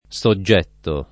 SoJJ$tto] agg. e s. m. — ormai rari i latinismi subietto e subbietto, anche in scritti dottrinali — dell’uso ant. la variante suggetto [SuJJ$tto]: Amor del quale i’ son sempre suggetto [